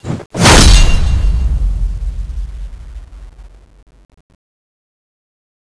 Index of /server/sound/weapons/tfa_cso/dreadnova
charge_release.wav